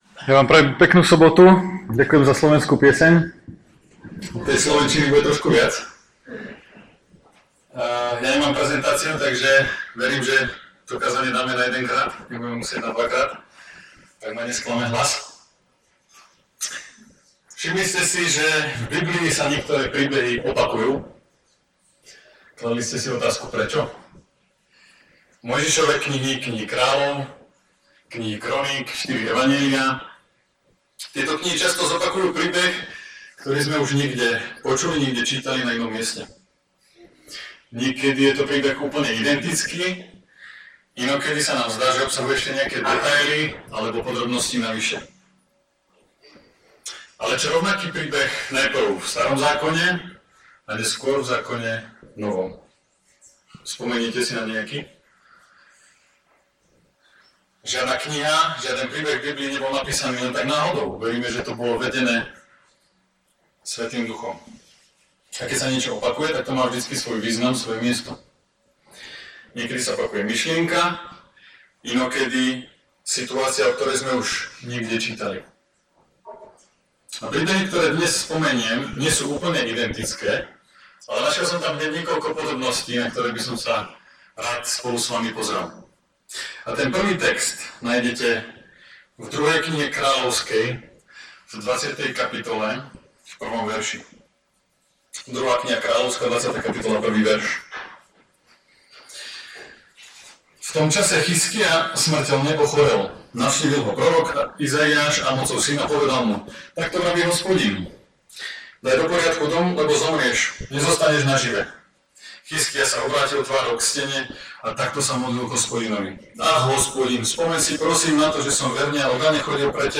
Omluvte sníženou kvalitu zvuku.